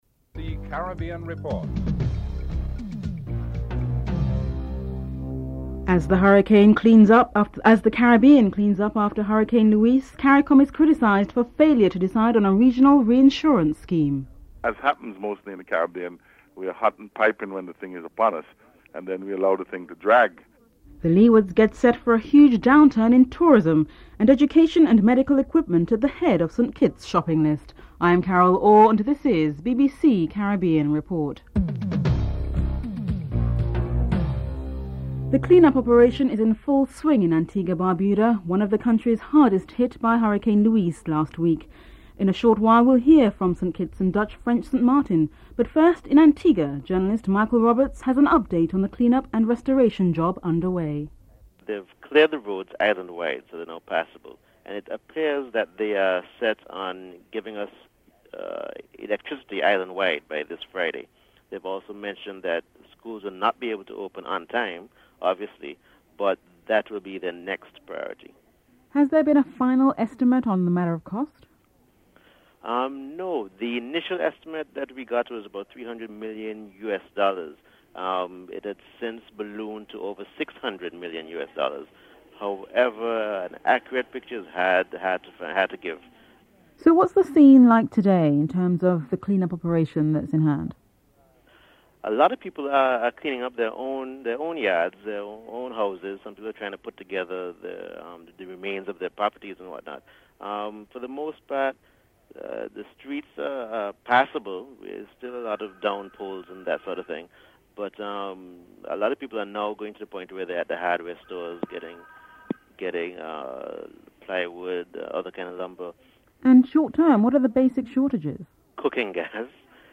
Meanwhile, both the Dutch and French side of St. Martin have been assessing the damages and residents speak about the cleanup efforts.
Interviews with various travel agencies in London on booking a flight to Antigua revealed mixed responses as well as some confusion.